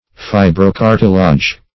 Fibrocartilage \Fi`bro*car"ti*lage\, n. [L. fibra a fiber + E.